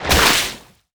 water_spell_impact_hit_01.wav